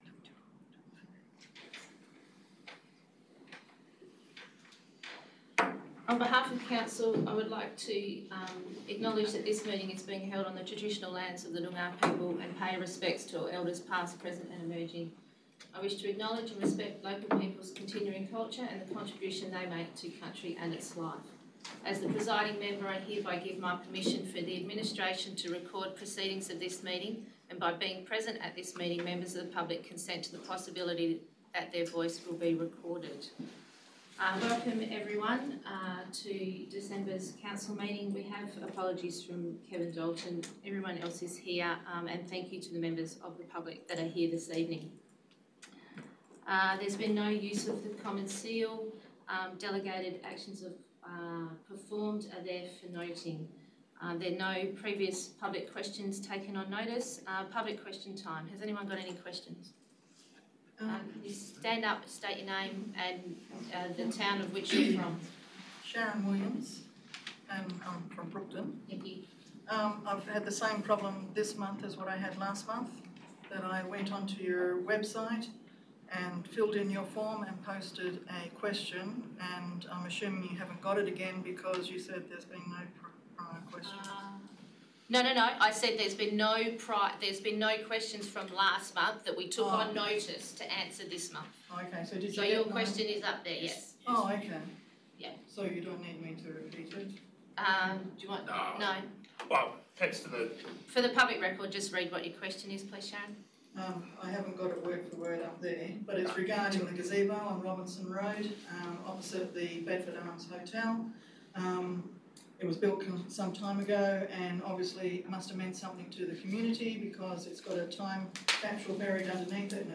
19 December 2024 - Ordinary Meeting of Council » Shire of Brookton
19 December 2024 - Ordinary Meeting of Council - Public Recording (45.12 MB)